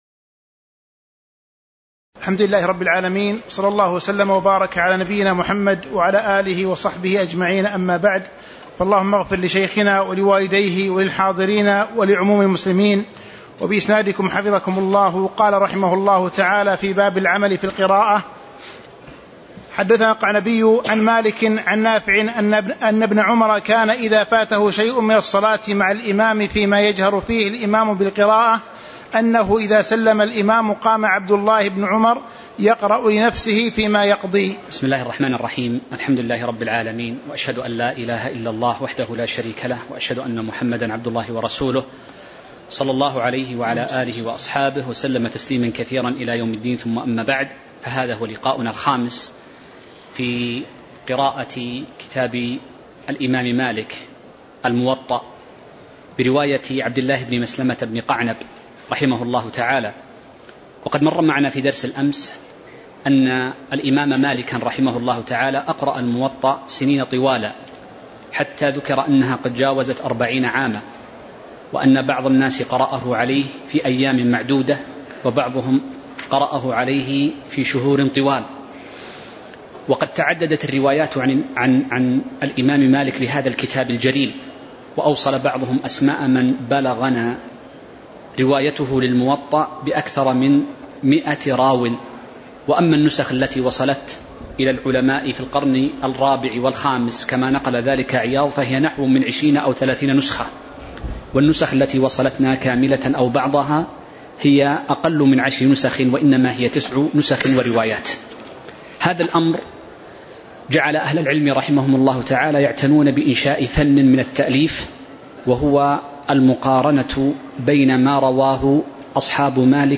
تاريخ النشر ٢٢ رجب ١٤٤٥ هـ المكان: المسجد النبوي الشيخ